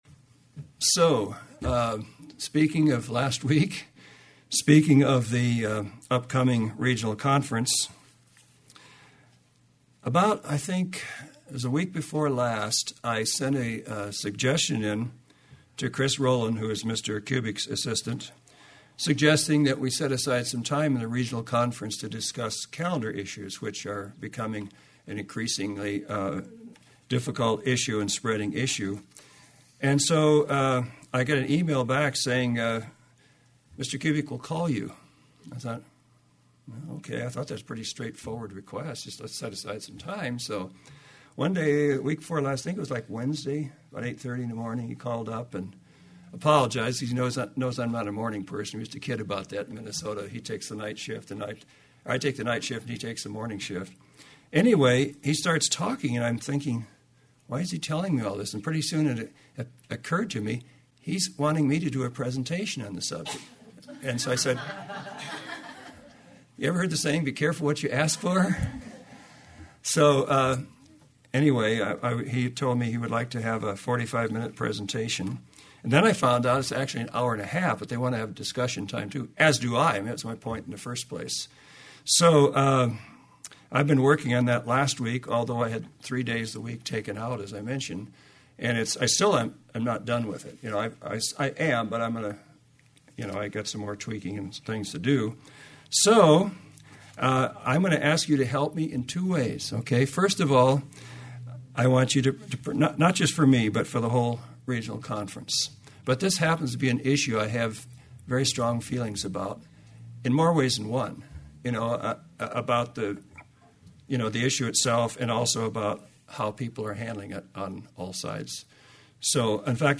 UCG Sermon Notes Doctrinal Controversy and Spiritual Unity Introduction – As most of you probably know, new moons and calendar issues have become a growing concern in the church of God community.